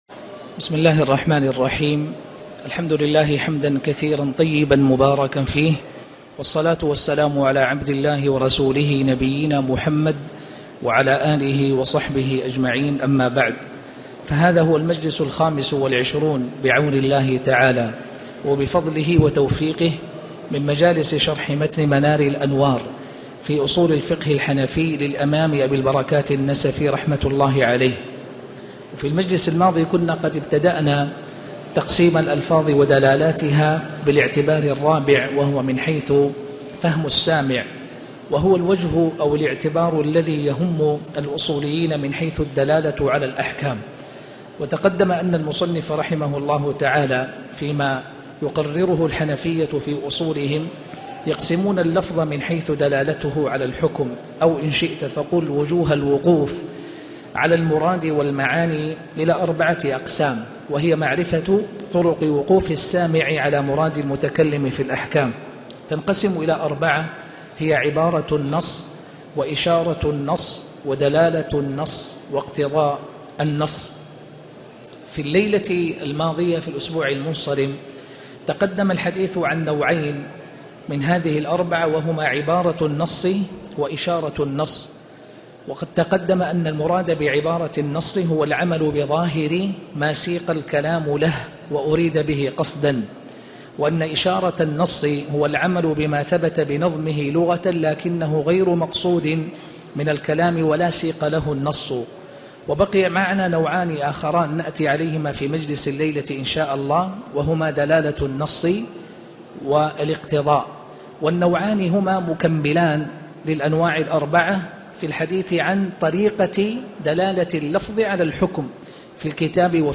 شرح منار الانوار الدرس 25